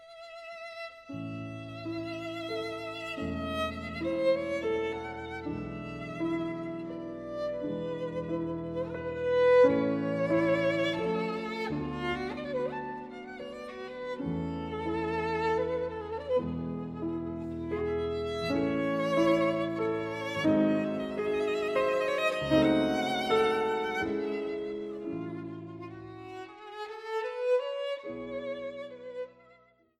captured in alluring sound of sparkling detail.